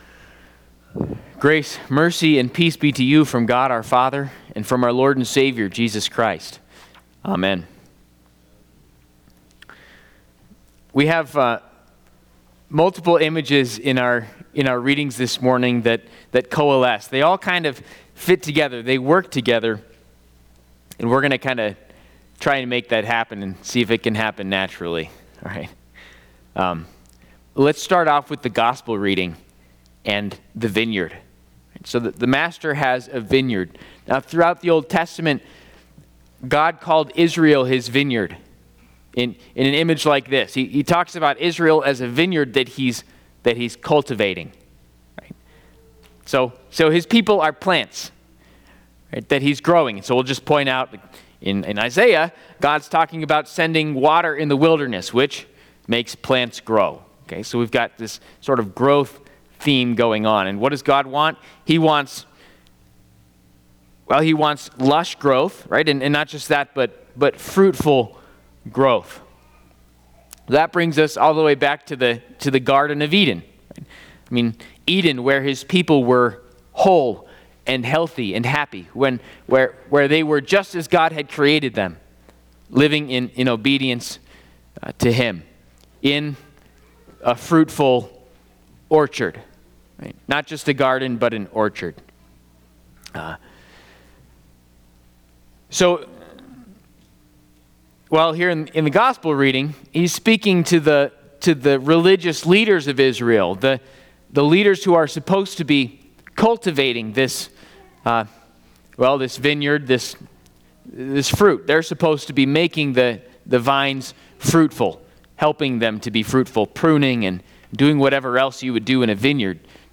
Fifth Sunday in Lent&nbsp